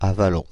Avallon (French pronunciation: [avalɔ̃]
Fr-Avallon.ogg.mp3